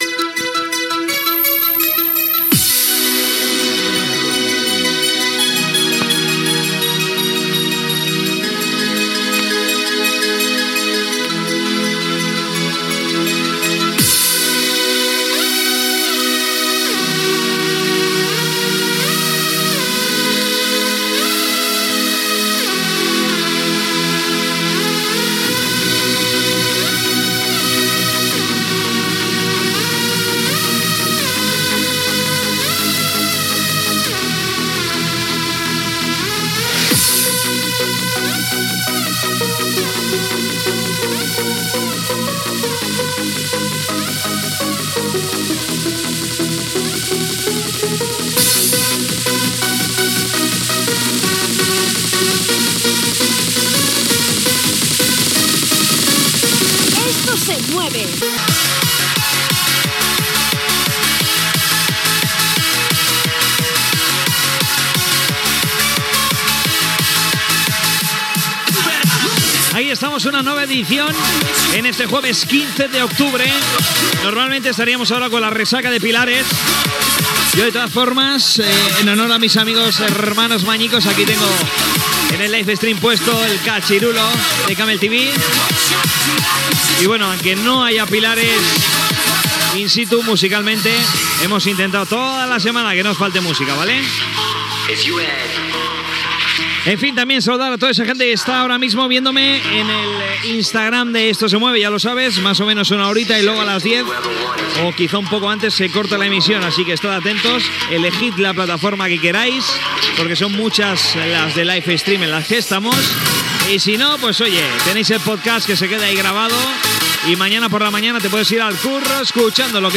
Indicatiu del progarama, presentació, plataformes on es pot escoltar el programa, tema musical, salutacions
Musical